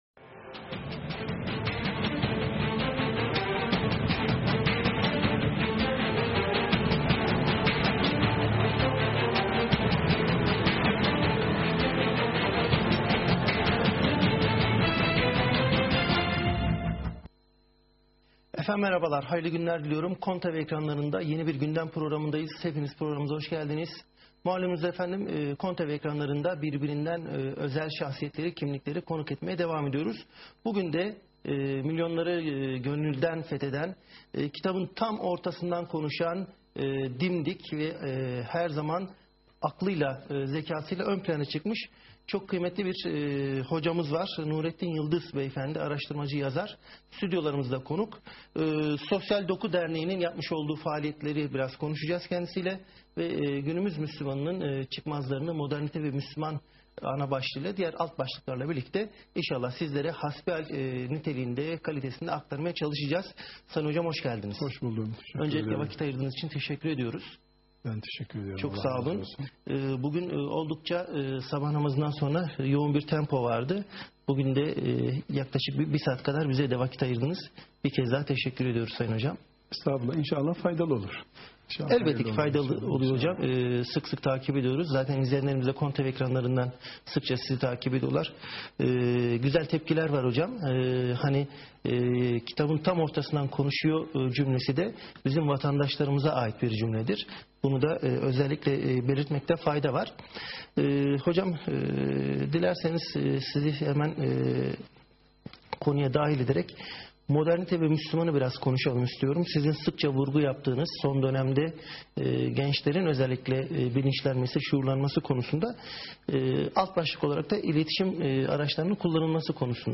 9) KONYA TV Canlı Yayın 1.Bölüm | Sosyal Doku TV